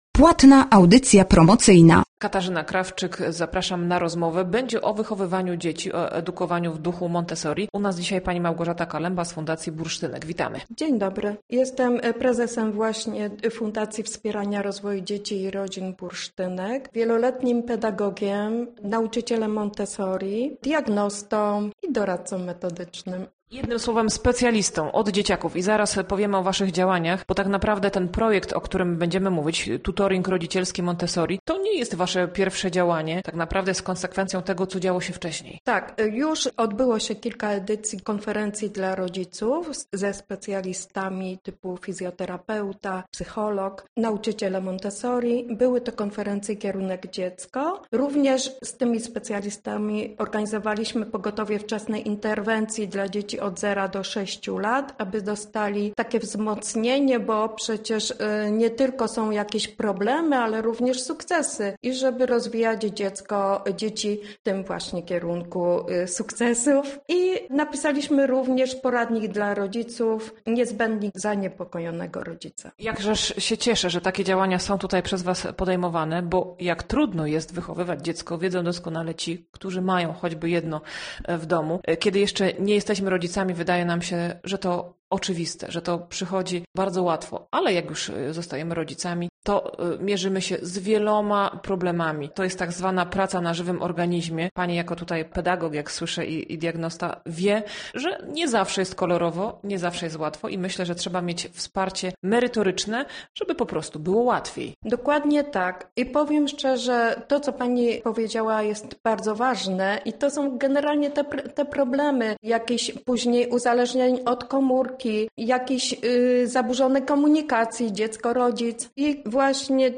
Ta rozmowa zaciekawi zwłaszcza rodziców dzieci do 10 roku życia. Będzie o wychowywaniu i edukowaniu w duchu Montessori.